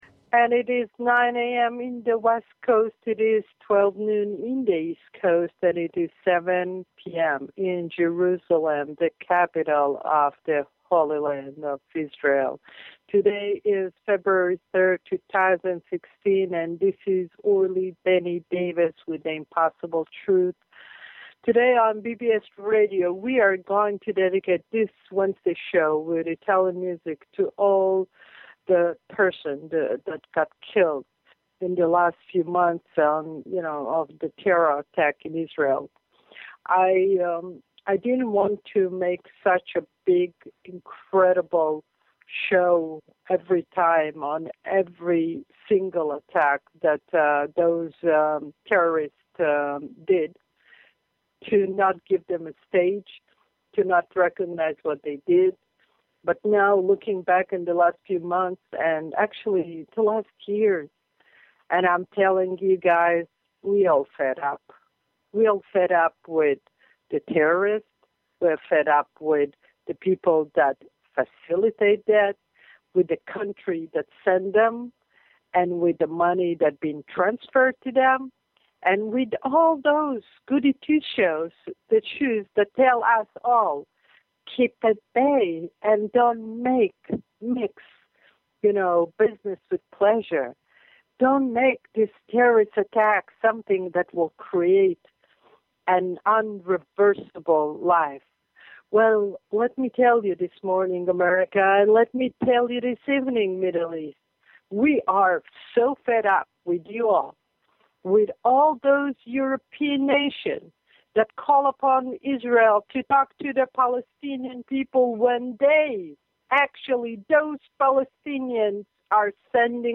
The Impossible Truth on BBS Radio.